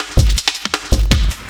LOOP06SD07-L.wav